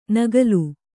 ♪ nagalu